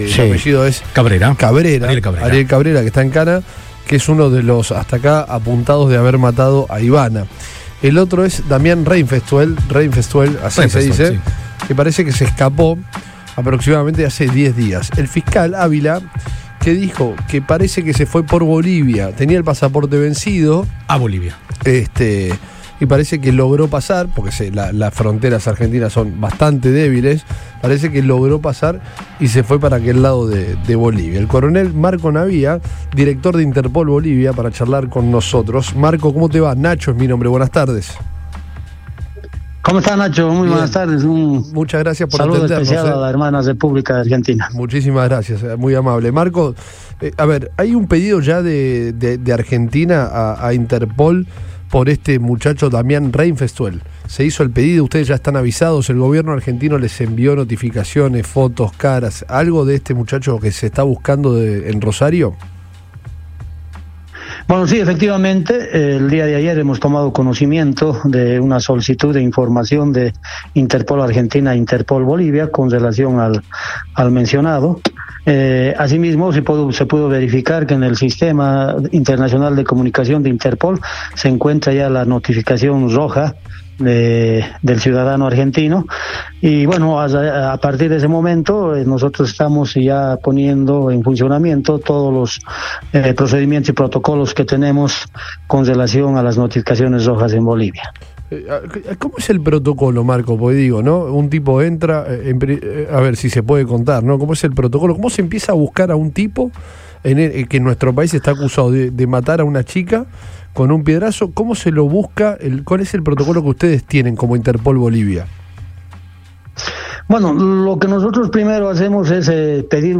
En el programa “Todo Pasa” de Radio Boing 97.3 habló el jefe de Interpol Bolivia, Marco Antonio Nadia y ratificó que este miércoles recibieron el pedido de la captura